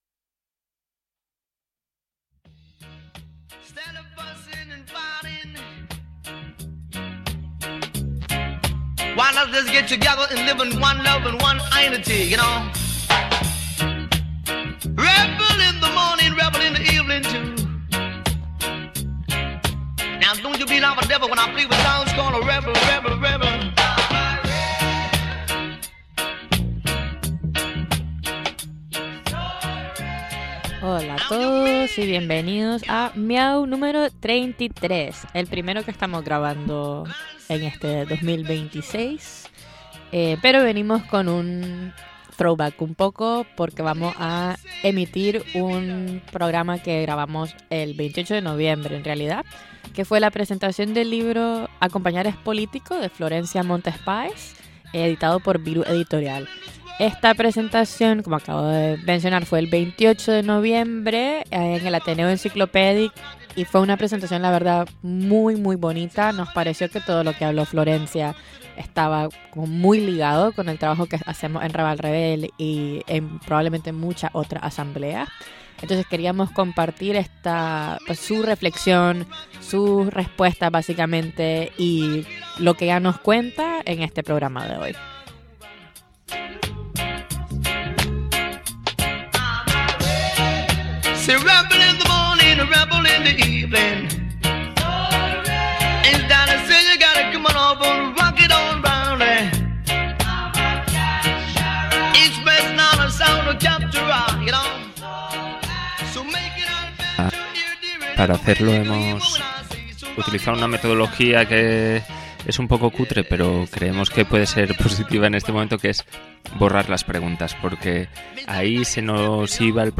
MIAU! #33 – Presentación del libro ACOMPAÑAR ES POLITICO
La sesión fue tan potente, que teníamos que compartir la grabación.